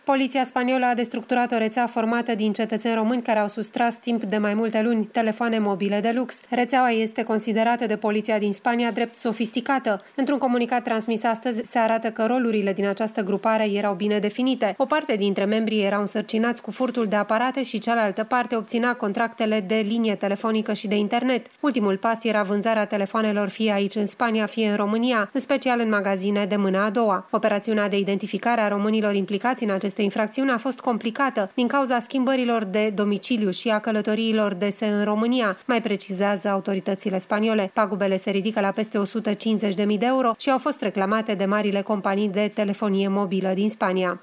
În Spania,10 români au fost reţinuţi pentru furt de telefoane mobile de lux şi pagube de peste 150 de mii de euro aduse companiilor de telefonie. O corespondenţă